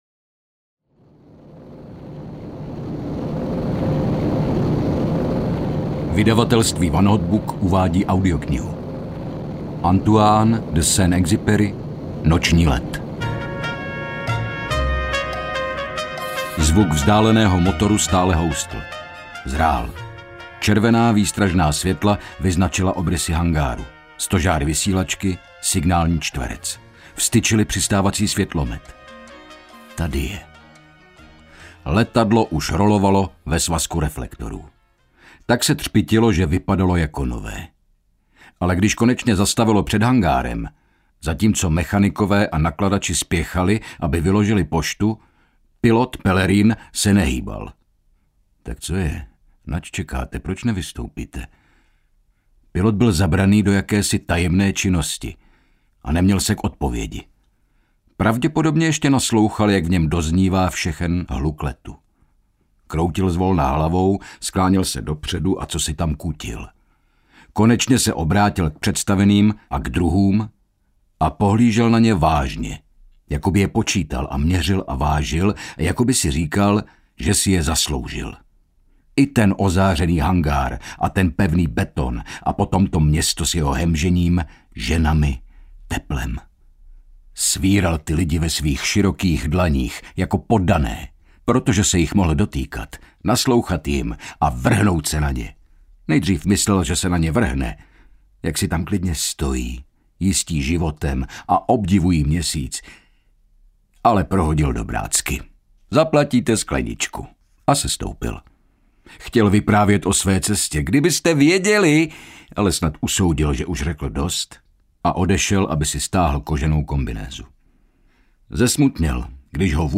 Noční let audiokniha
Ukázka z knihy
• InterpretHynek Čermák